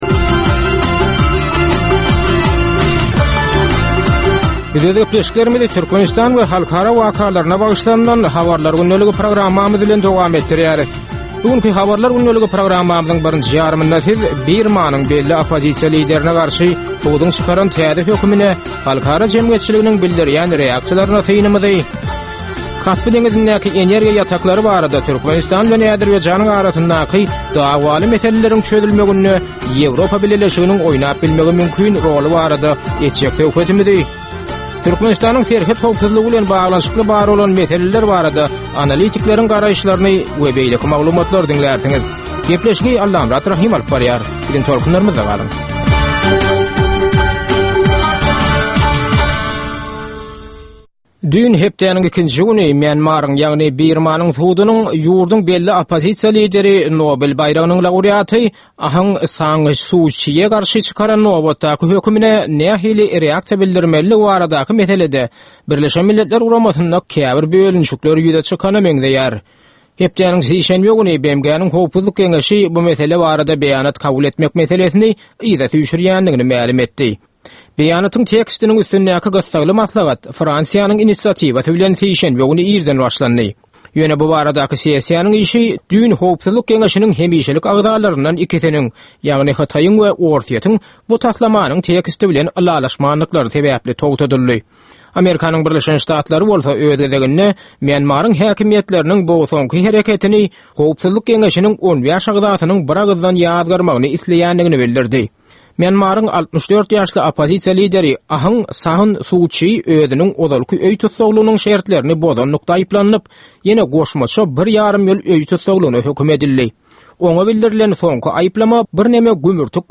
Türkmenistandaky we halkara arenasyndaky sonky möhüm wakalar we meseleler barada ýörite informasion-analitiki programma. Bu programmada sonky möhüm wakalar we meseleler barada ginisleýin maglumatlar, analizler, synlar, makalalar, söhbetdeslikler, reportažlar, kommentariýalar we diskussiýalar berilýär.